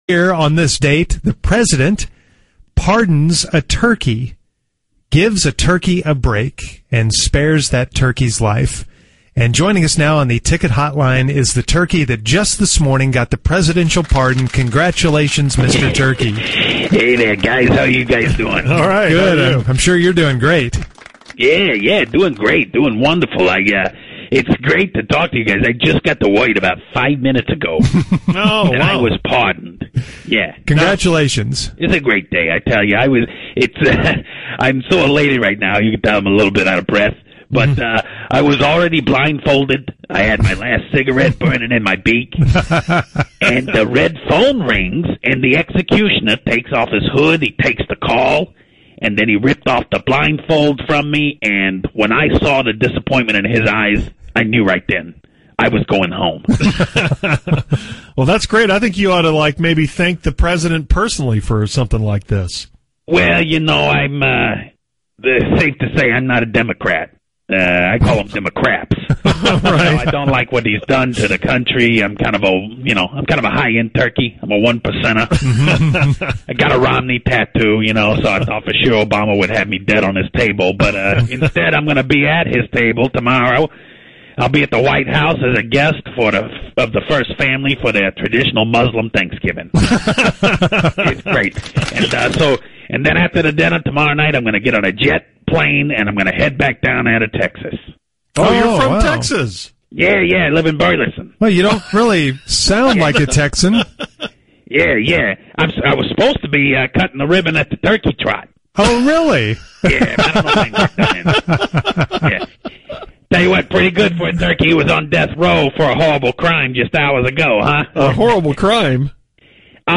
The Musers interview this year’s pardoned turkey. He sounds a lot like Tom the Turkey who was pardoned twice before, but he has some conflicting political views.